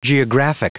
Prononciation du mot geographic en anglais (fichier audio)
Prononciation du mot : geographic